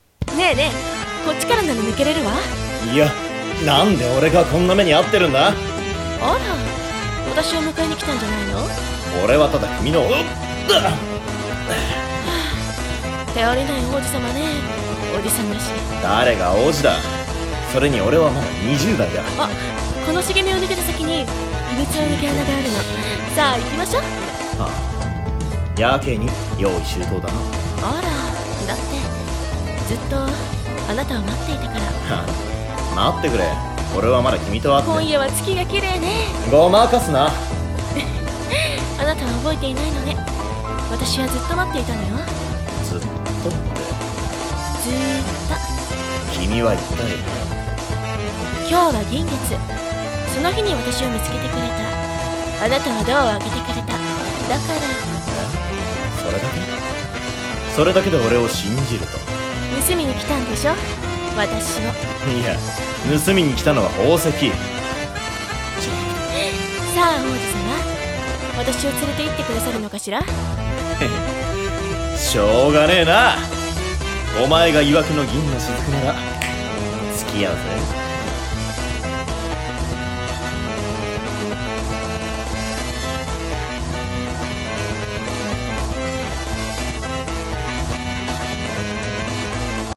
Hunt for silver drop】二人声劇